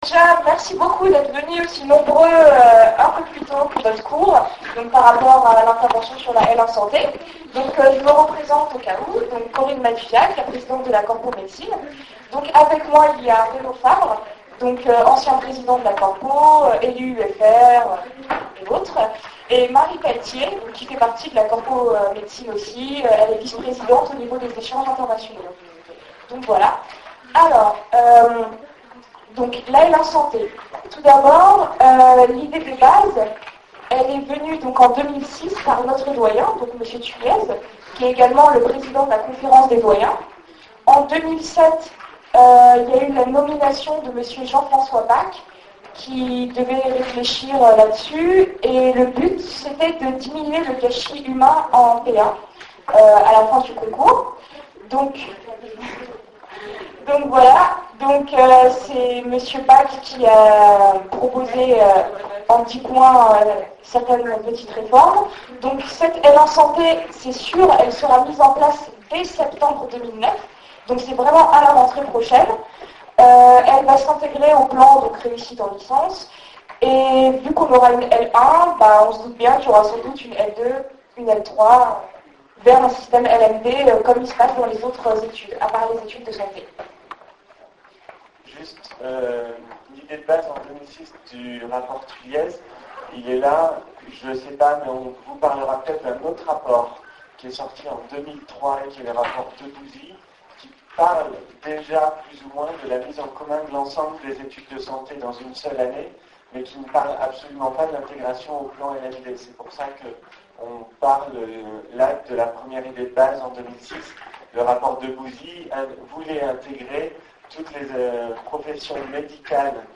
Présentation le 6 novembre 2008 par la Corpo de Rouen des réformes de la licence santé